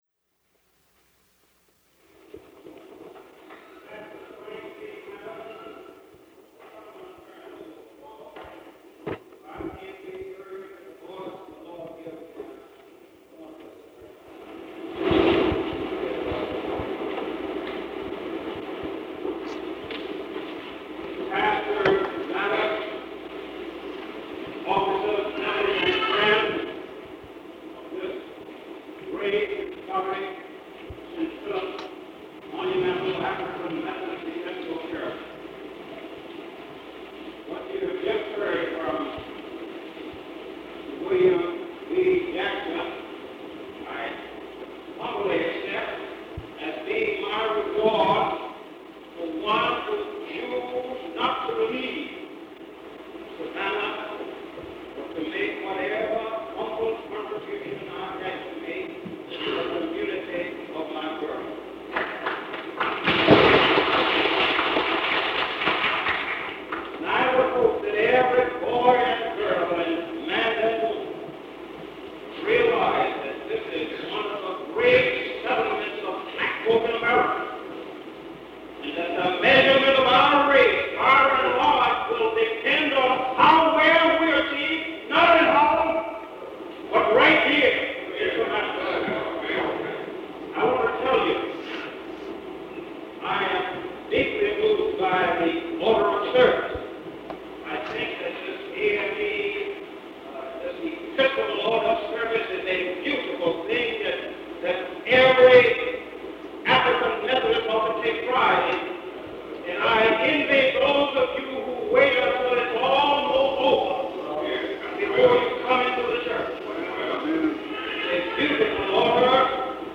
Object Name Tape, Magnetic